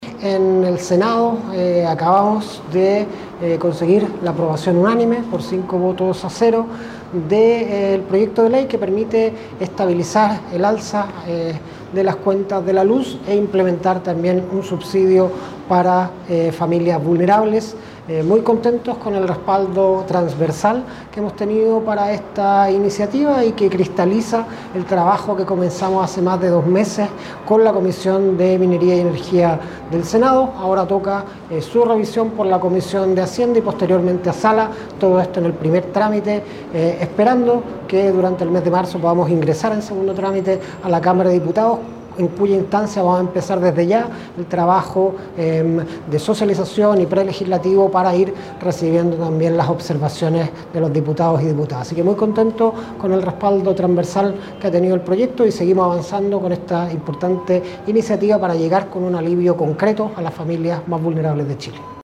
El Ministro de Energía, Diego Pardow, resaltó la importancia del respaldo obtenido.